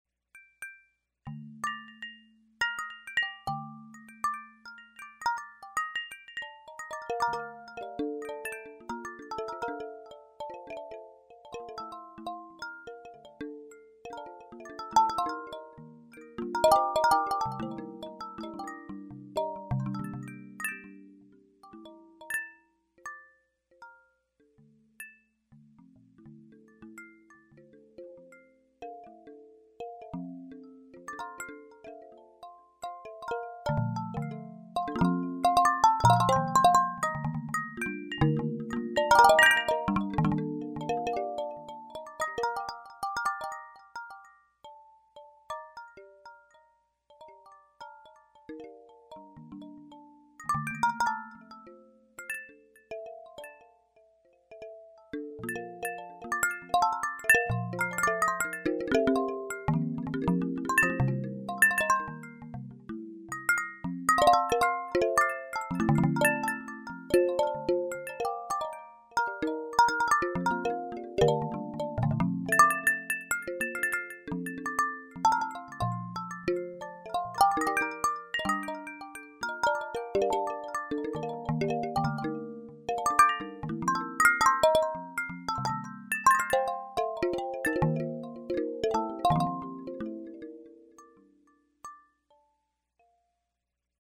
Since the music is in stereo, listening with high quality headphones or through a good home stereo system is advised.
Each of these audio examples were created by simply tilting the device to roll the balls around, or by just setting the gravity direction and laying the device flat.
>   Rain on Bamboo
Chimes_2.mp3